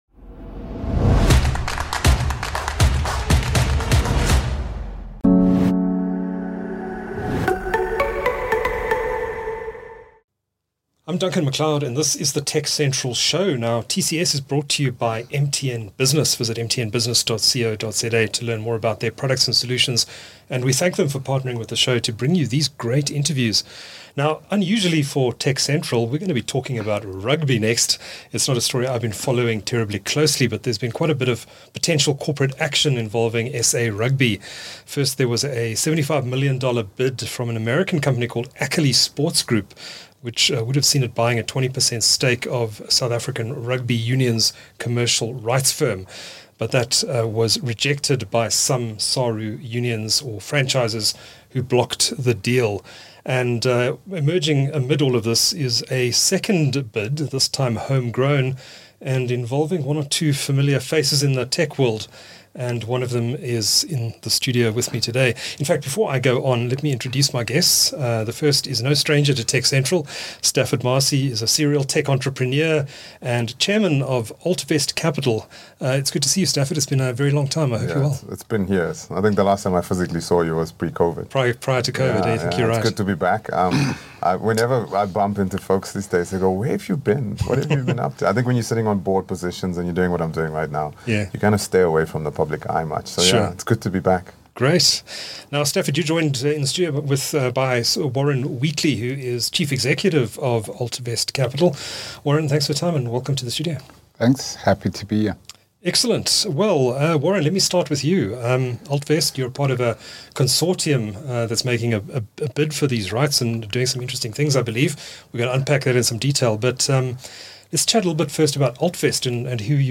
The TechCentral Show (TCS, for short) is a tech show produced by South Africa's leading technology news platform. It features interviews with newsmakers, ICT industry leaders and other interesting people.